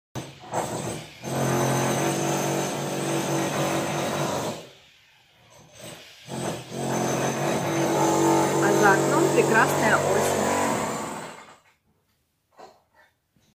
工事、改装の音 無料ダウンロードとオンライン視聴はvoicebot.suで